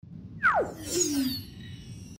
На этой странице собраны звуки голограмм — загадочные и футуристические аудиоэффекты, напоминающие технологии из научной фантастики.
Исчезновение голографического образа